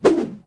footswing6.ogg